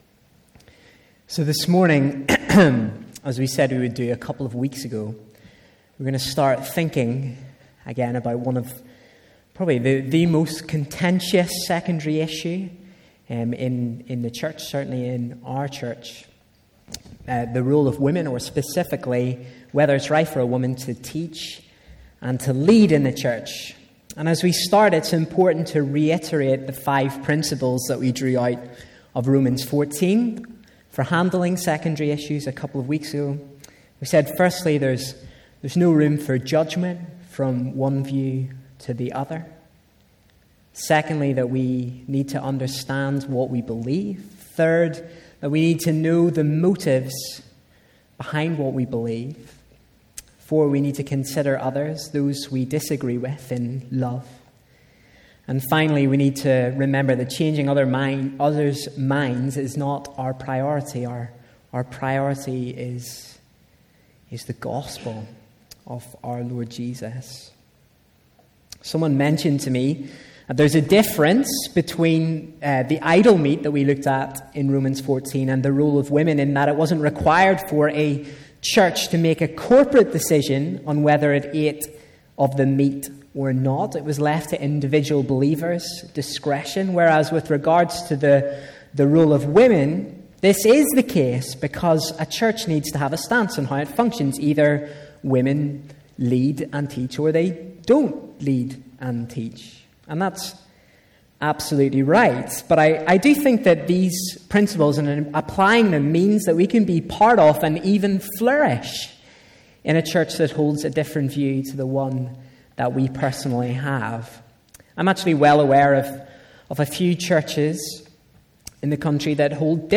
A message from the series "Leadership."